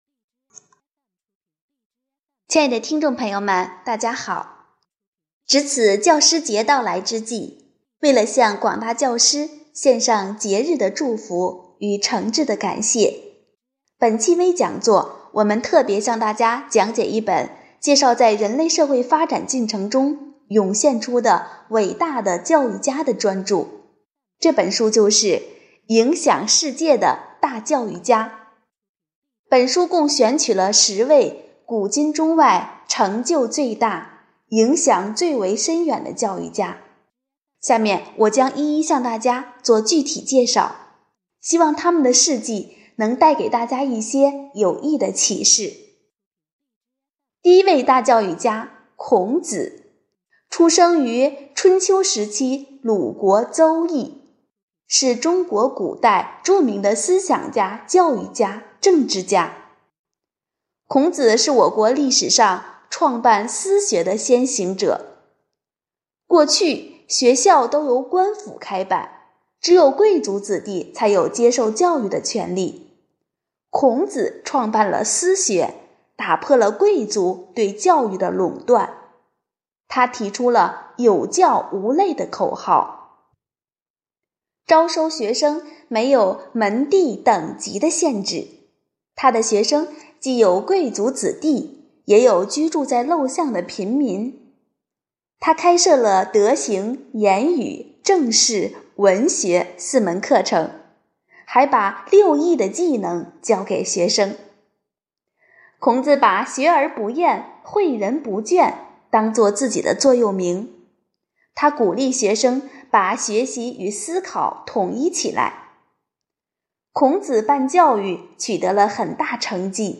活动预约 - 【讲座】丰图讲堂 | 一束灵魂的光照亮世界——影响世界的10大教育家